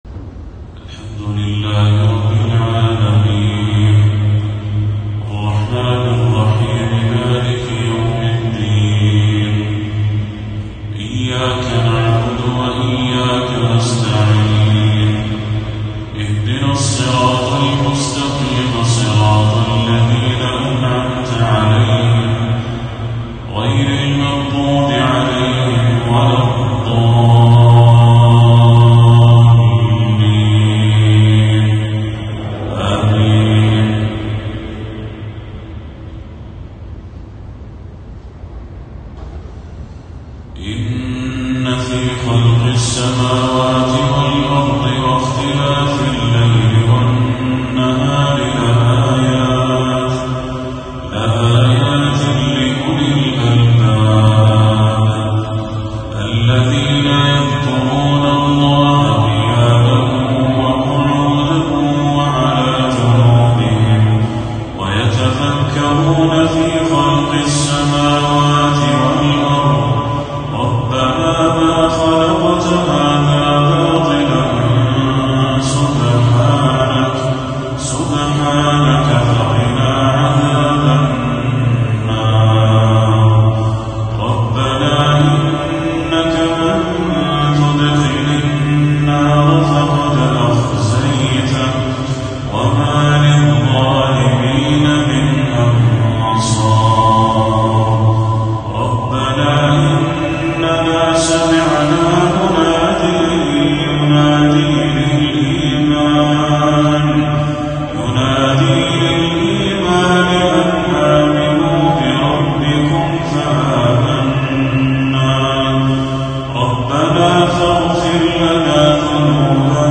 تلاوة بديعة تأسر القلب لخواتيم سورة آل عمران للشيخ بدر التركي | عشاء 11 صفر 1446هـ > 1446هـ > تلاوات الشيخ بدر التركي > المزيد - تلاوات الحرمين